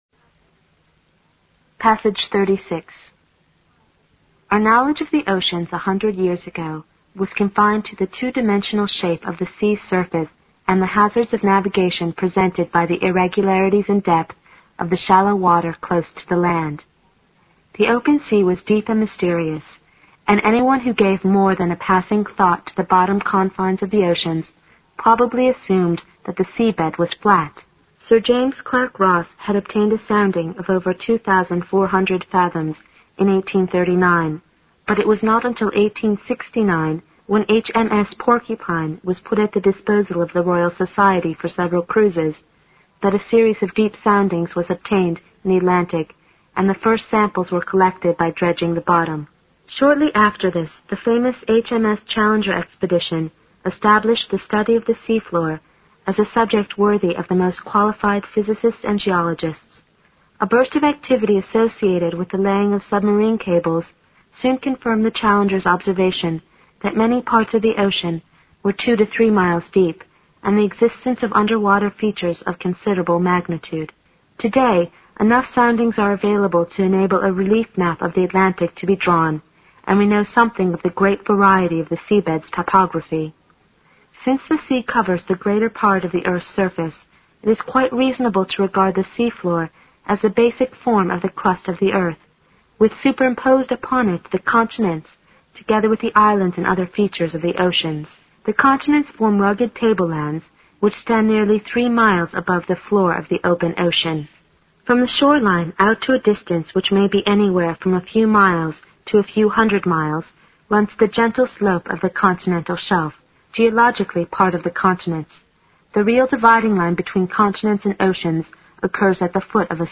新概念英语85年上外美音版第四册 第36课 听力文件下载—在线英语听力室